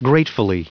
Prononciation du mot gratefully en anglais (fichier audio)
Prononciation du mot : gratefully